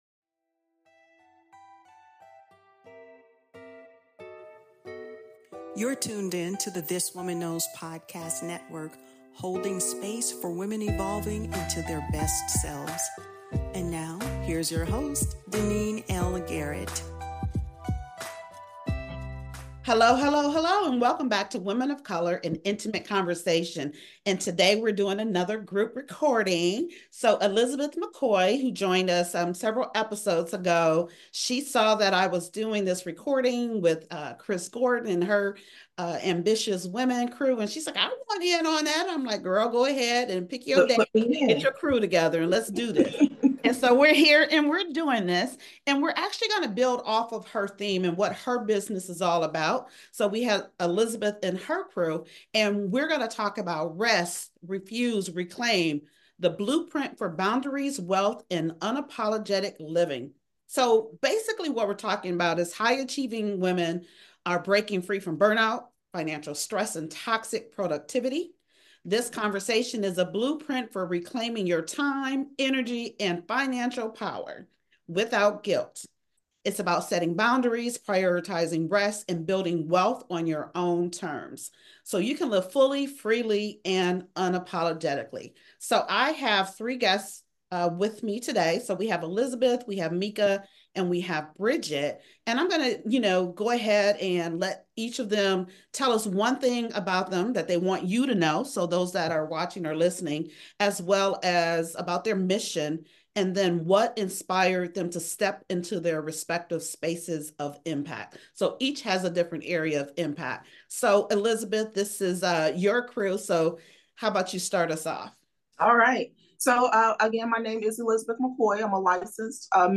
Featuring voices from wellness, finance, therapy, and entrepreneurship, this conversation is a timely reminder that worth is not tied to productivity.